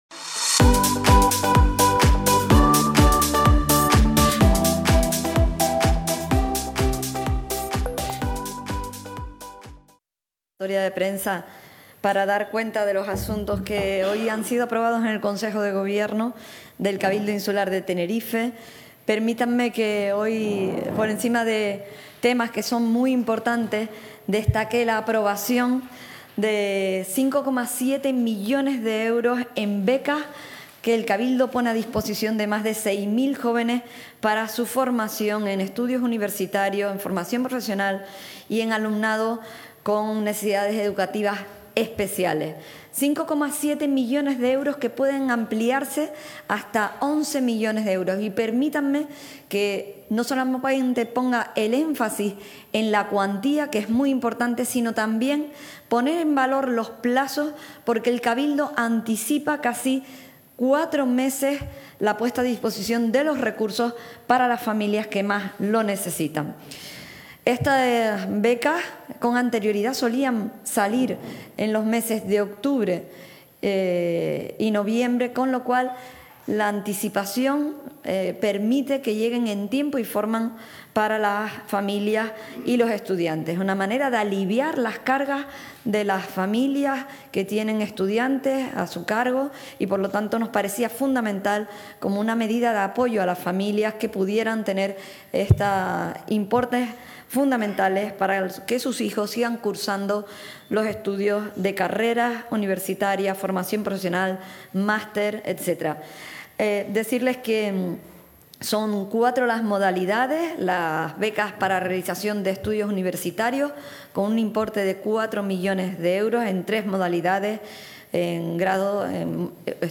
Recientemente emitido: Desde el Salón Noble del Palacio Insular, rueda de prensa para informar sobre los Acuerdos tomados en la sesión del Consejo de Gobierno.